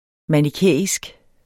Udtale [ maniˈkεˀisg ]